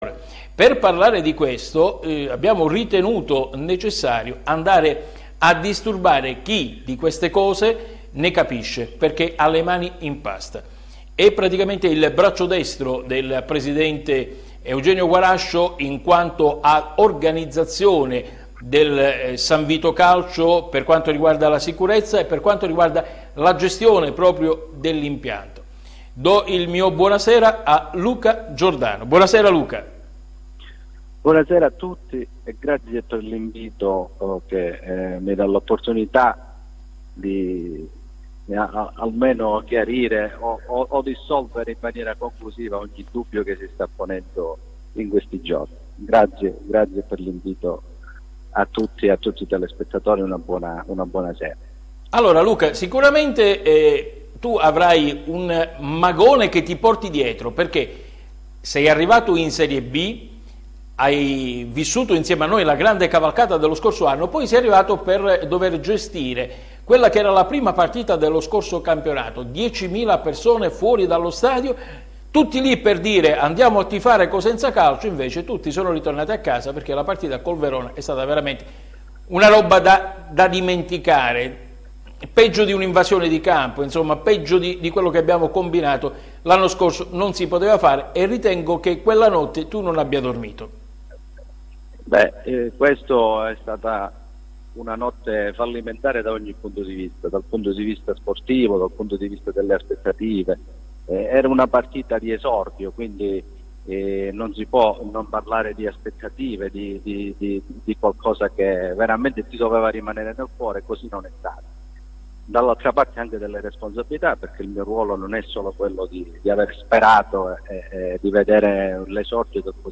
Lo ha fatto intervenendo telefonicamente nel corso della trasmissione Soccer Night